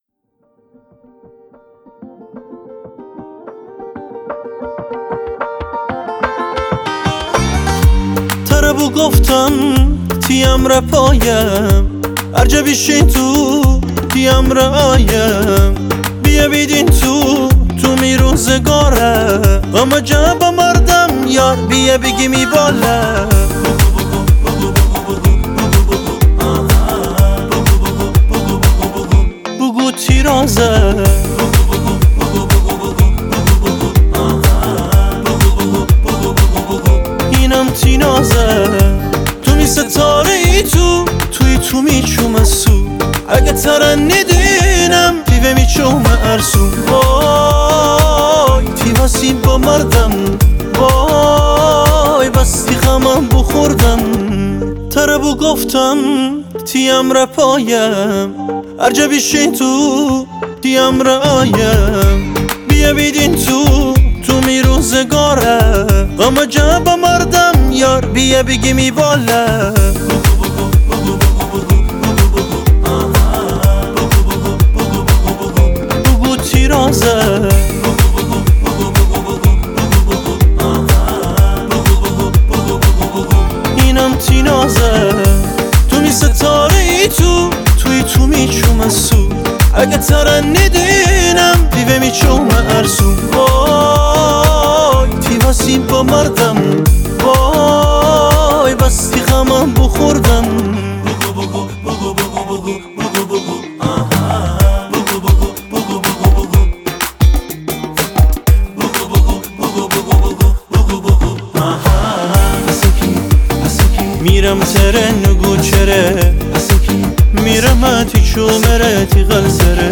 سنتی / محلی